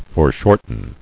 [fore·short·en]